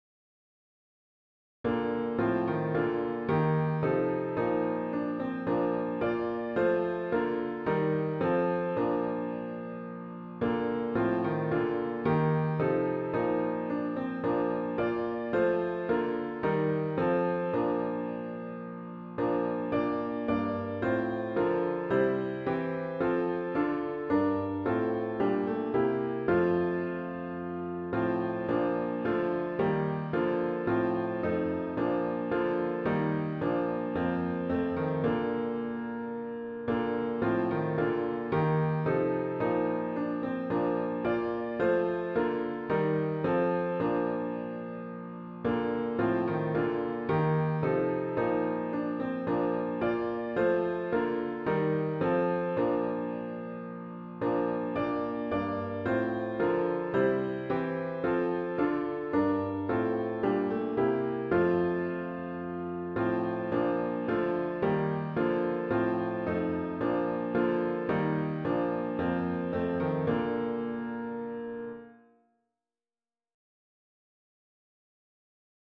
OPENING HYMN   “O Lord, How Shall I Meet You”   GtG 104   (Verses 1-2)